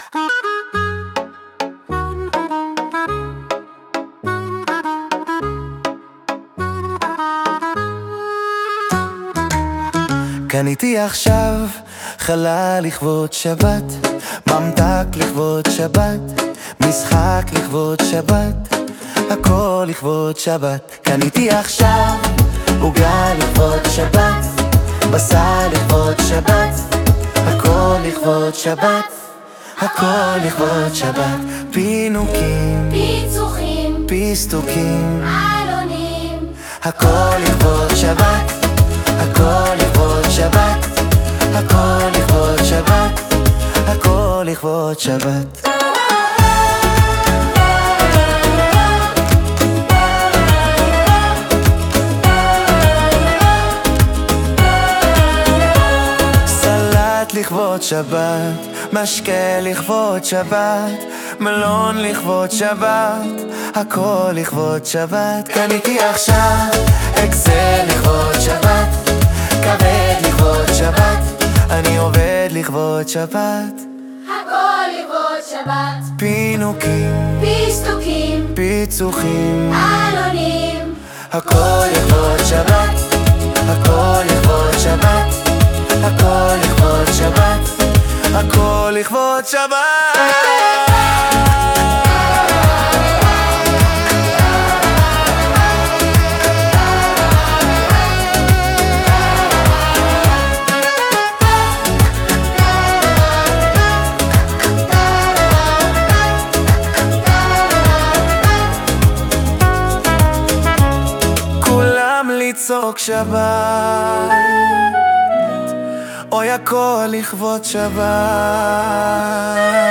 מה אומרים על זה שיר שהבינה מלאכותית יצרה או שלא תנו נימוקים לכאן או לכאן פיסטוקים לכבוד שבת.mp3
האוטוטיון הוא של הAI או שזה היה בוואקל המקורי?
המלודיה של הסקסופון או מה שזה לא יהיה היא של ה AI?